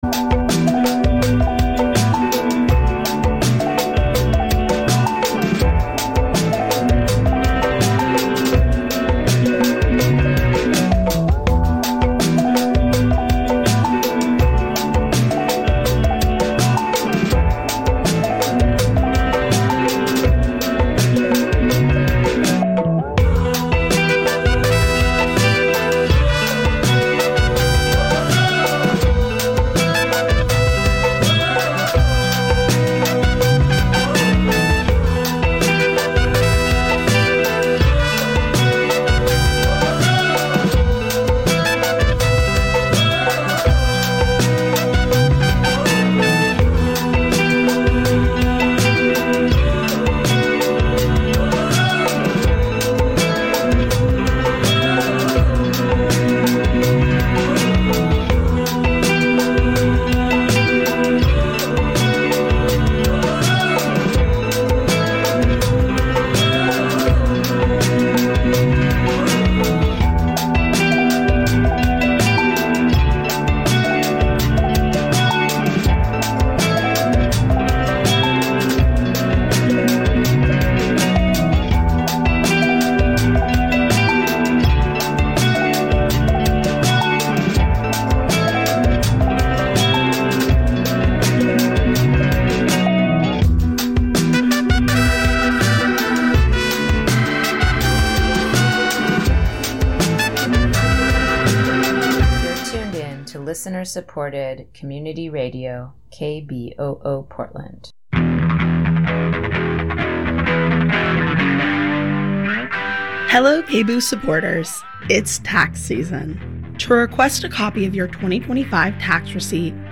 This Way Out is the only internationally distributed weekly LGBTQ radio program, currently airing on some 200 local community radio stations around the world. The award-winning half-hour magazine-style program features a summary of some of the major news events in or affecting the queer community (NewsWrap), in-depth coverage of major events, interviews with key queer figures, plus music, literature, entertainment — all the information and culture of a community on the move!